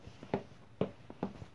Footsteps Snow
描述：Footsteps in snow
标签： fieldrecording footsteps now walking feet
声道立体声